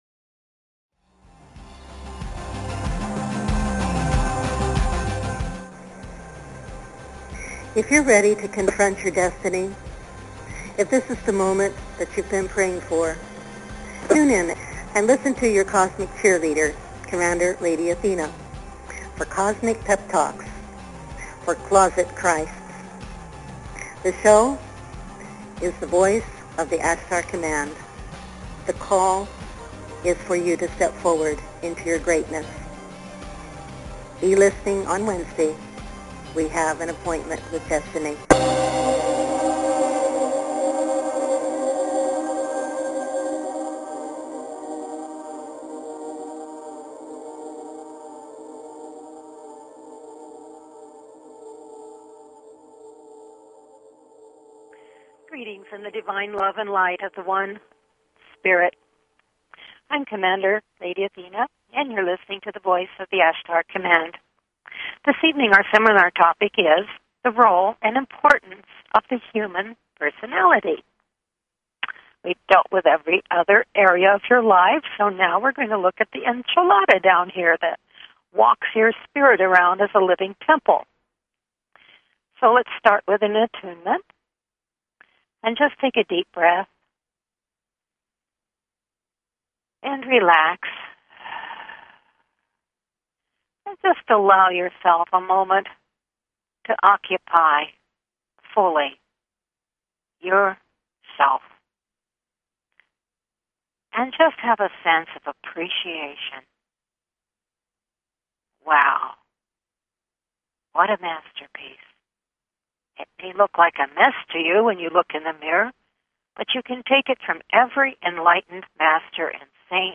Talk Show Episode, Audio Podcast, The_Voice_of_the_Ashtar_Command and Courtesy of BBS Radio on , show guests , about , categorized as
Various experiential processes, meditations and teachings evoke your Divine knowing and Identity, drawing you into deeper com